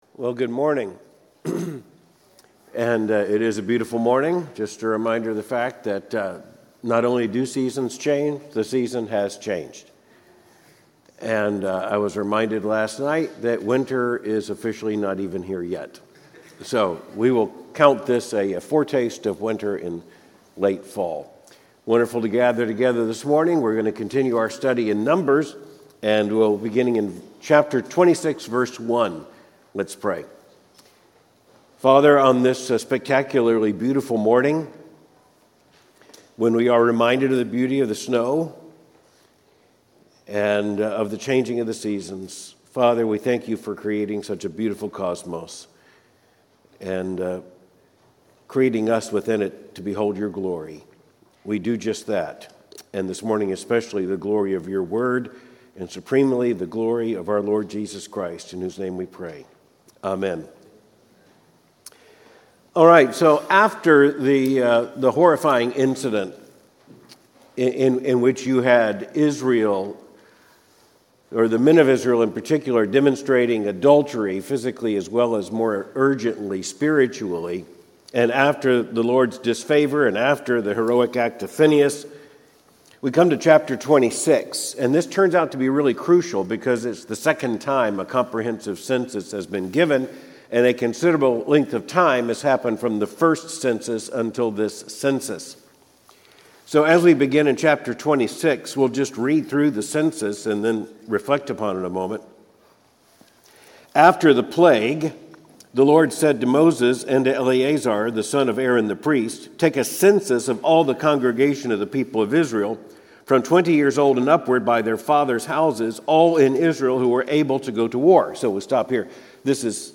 Cultural commentary from a Biblical perspective Third Avenue Baptist Church Louisville, KY Numbers 26-27 December 1, 2024